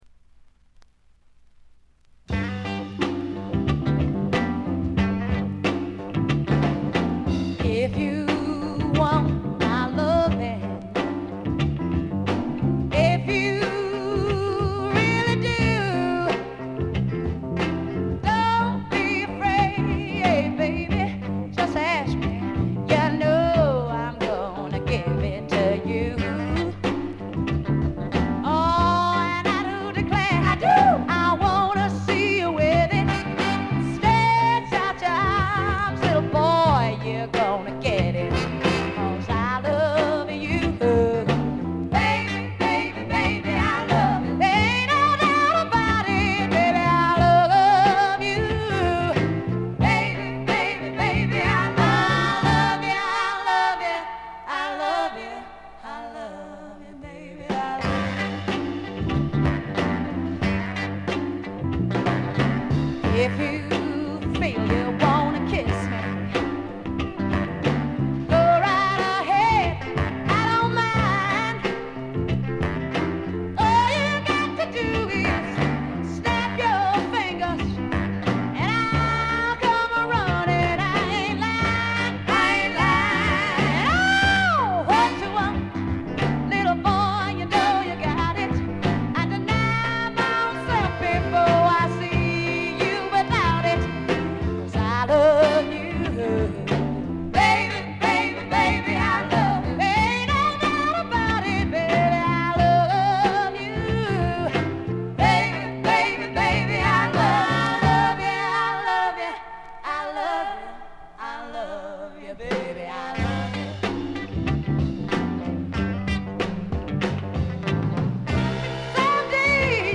ほとんどノイズ感無し。
試聴曲は現品からの取り込み音源です。
vocals, piano